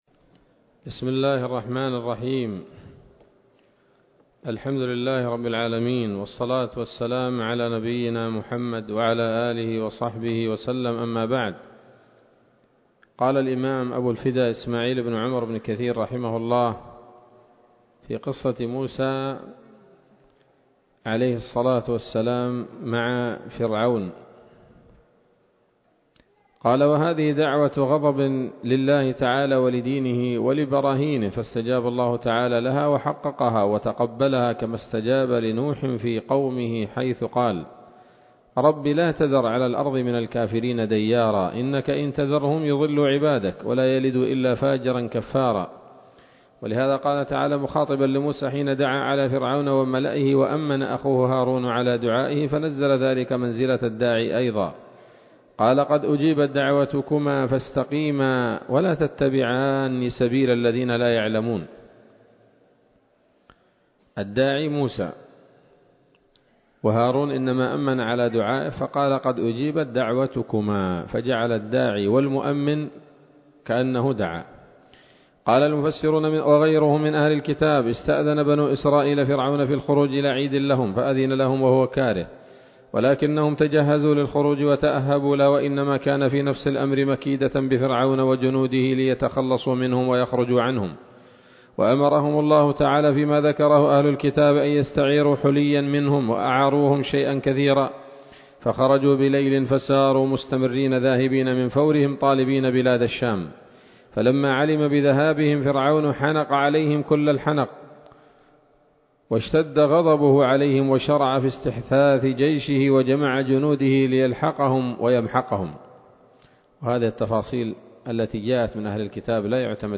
‌‌الدرس الثالث والتسعون من قصص الأنبياء لابن كثير رحمه الله تعالى